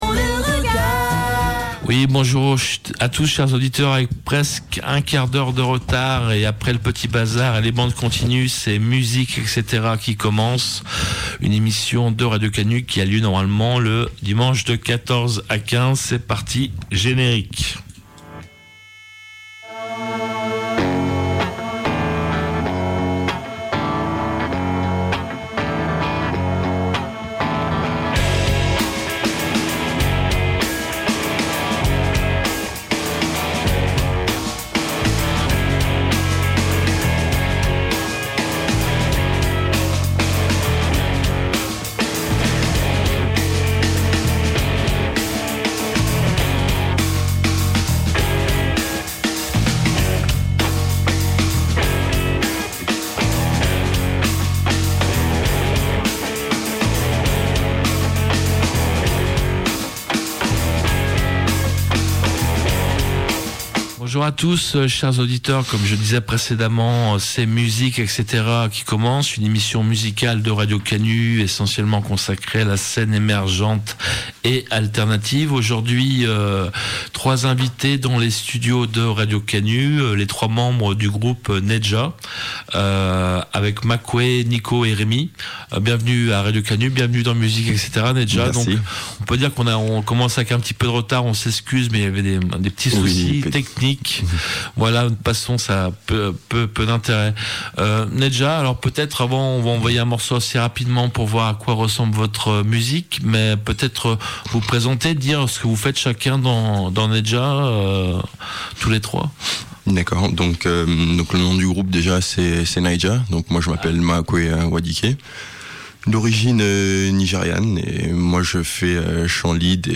Trois membres du groupe de reggae Nai-Jah étaient les invités de Musik-etc pour répondre à mes questions, jouer trois titres en live qui figureront sur leur prochain disque qui sortira en février/mars 2015 et évoquer leur cheminement musical.
Nai-Jah : quatuor atypique aux influences reggae. Un soubassophone en guise de basse, un batteur déchainé et un chanteur guitariste, auteur de compostions originales, ont mis en commun leurs univers totalement différents.